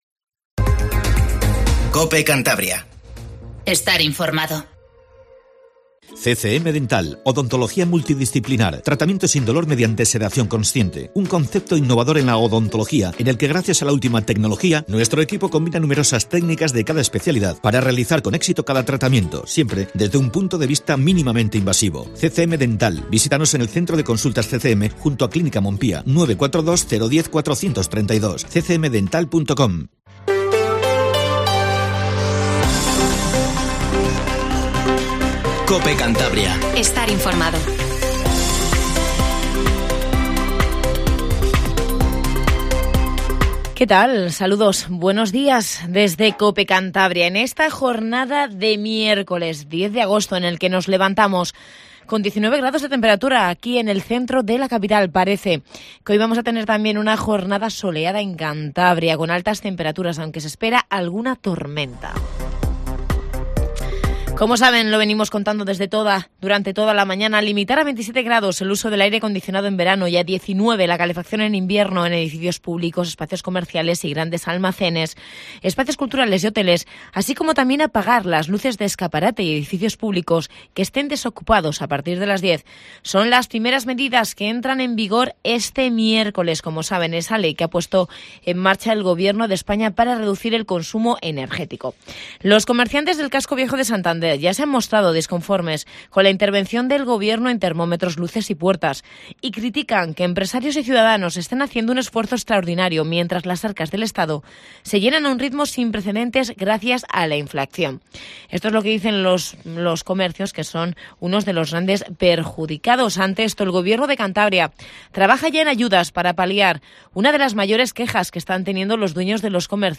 Informativo Matinal Cope